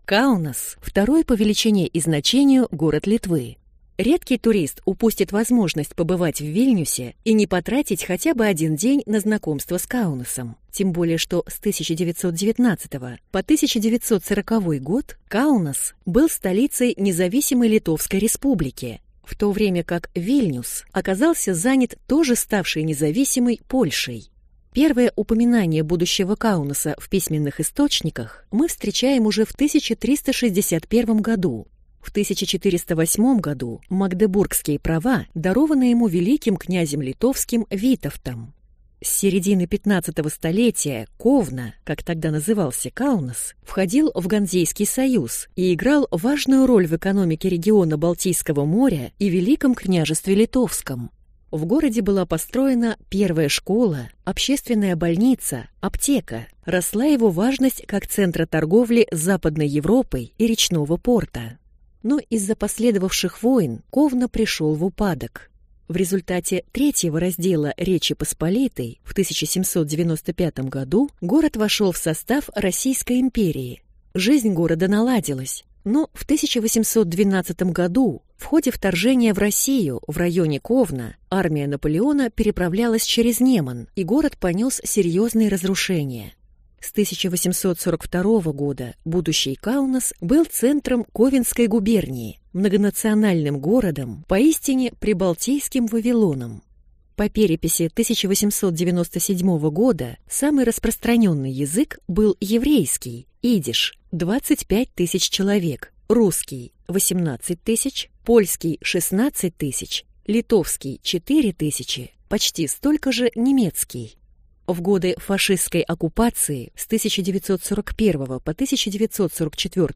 Аудиокнига Каунас. Аудиогид | Библиотека аудиокниг